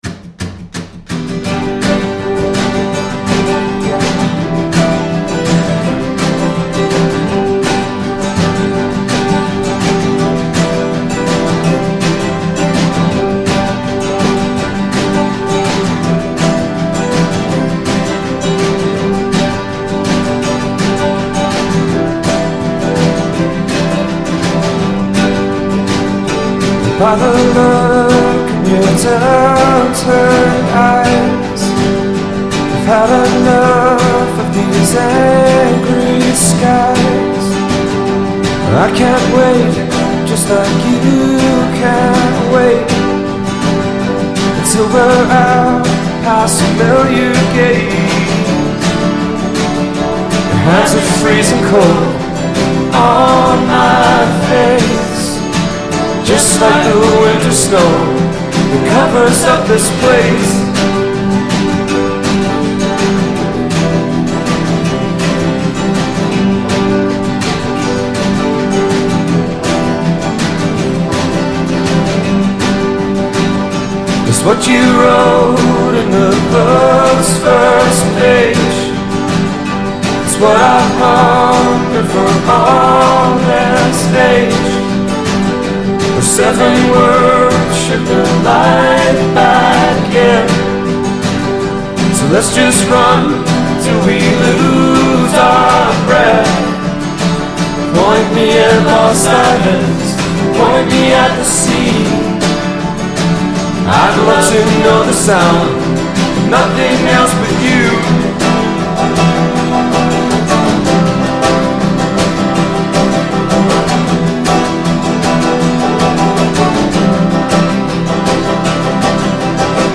straight-up, unpretentious rock